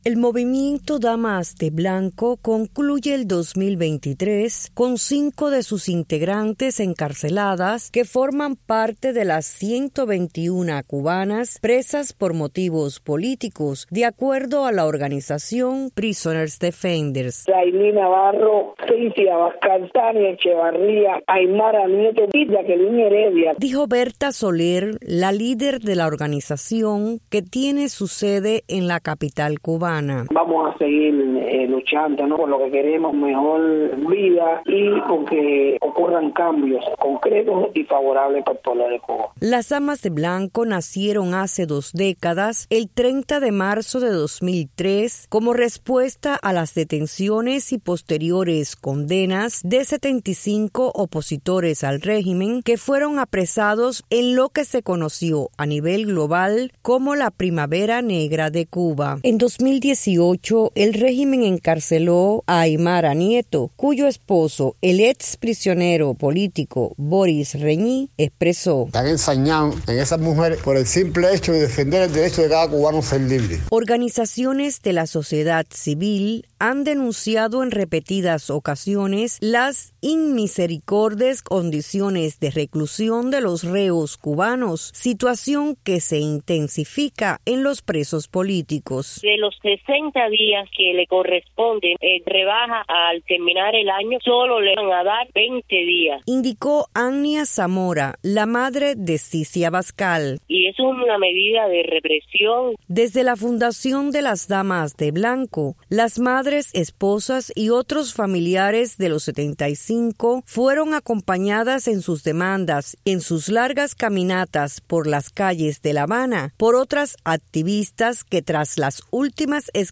Declaraciones de Berta Soler